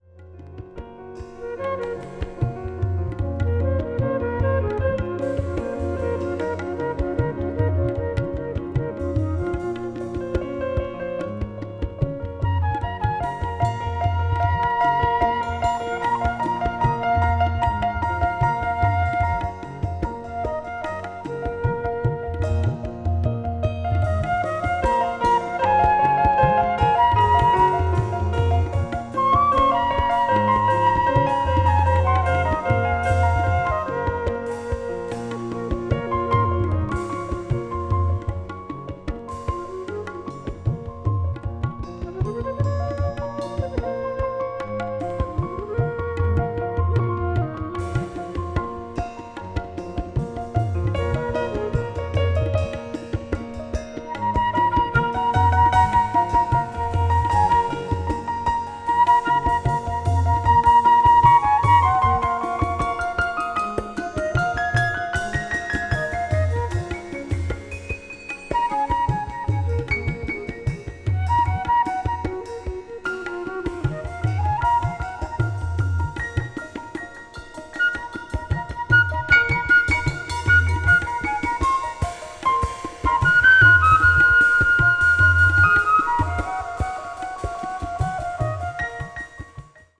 arab   contemporary jazz   ethnic jazz   world music